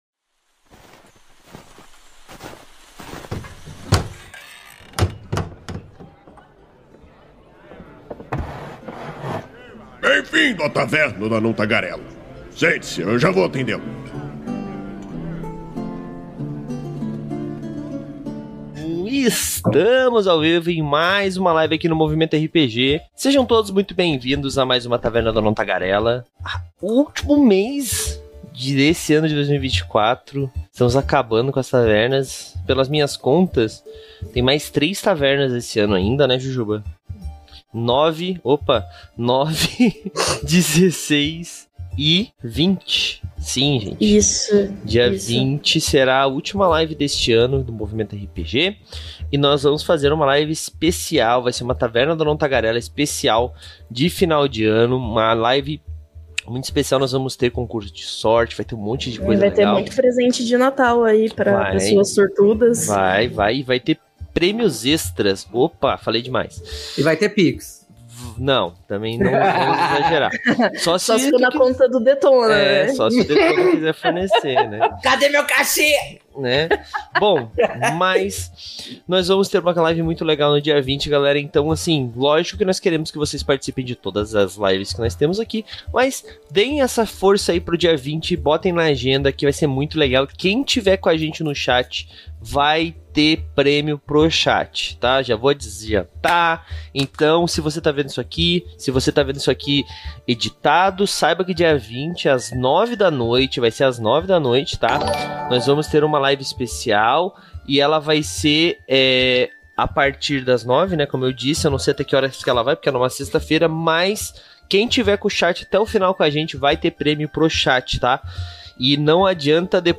Venha participar dessa conversa que vai desde o que é, de fato, um vilão, até o que mecanicamente faz um vilão interessante no seu RPG. A Taverna do Anão Tagarela é uma iniciativa do site Movimento RPG, que vai ao ar ao vivo na Twitch toda a segunda-feira e posteriormente é convertida em Podcast.